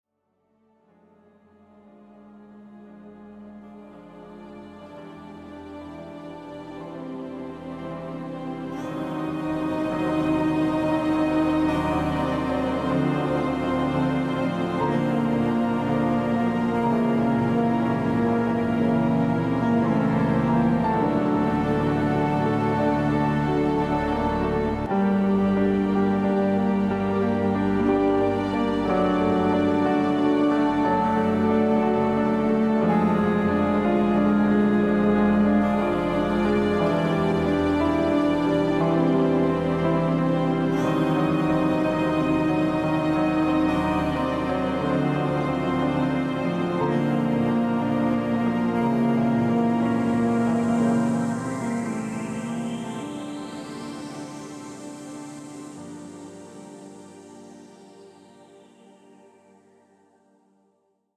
Instrumentalmusik